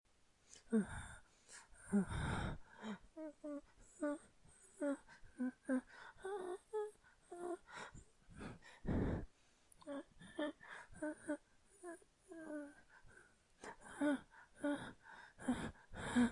闹鬼恐怖呻吟
描述：令人毛骨悚然的低音呻吟，带有回音。
Tag: 怪异 呻吟 恐怖 闹鬼